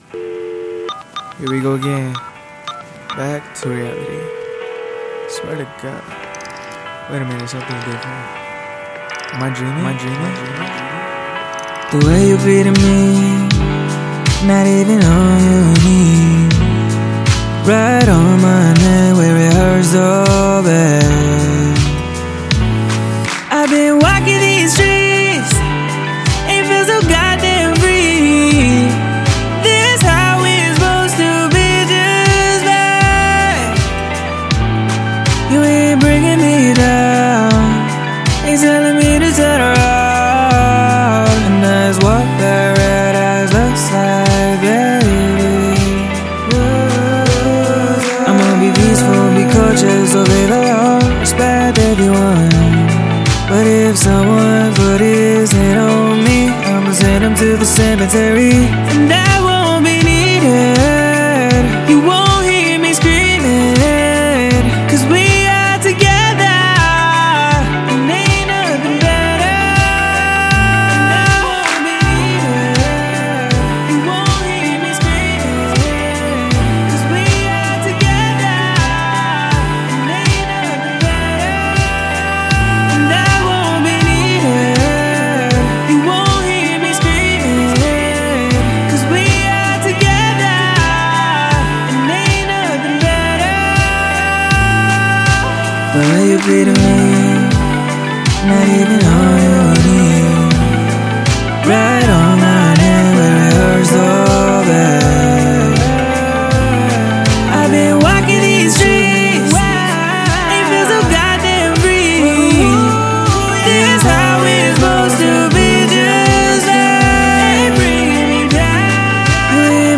E MINOR